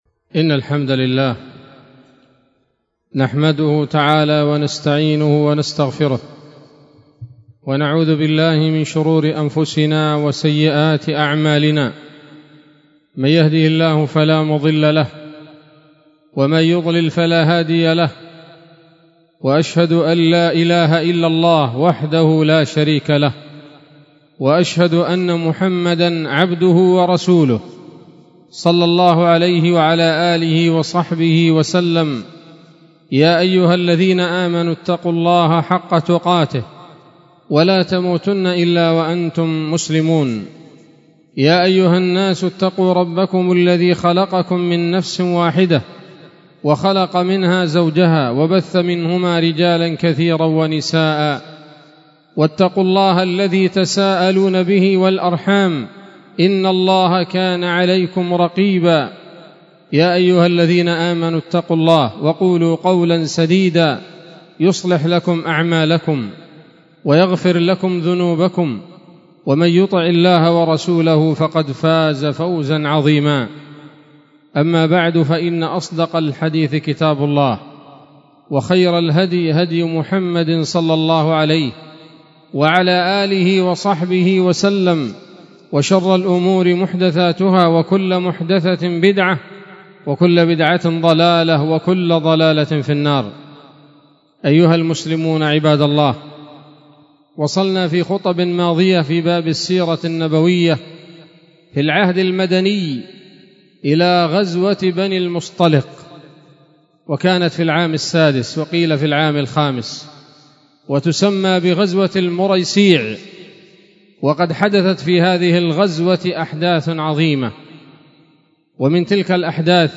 خطبة جمعة بعنوان: (( السيرة النبوية [24] )) 22 ذي الحجة 1445 هـ، دار الحديث السلفية بصلاح الدين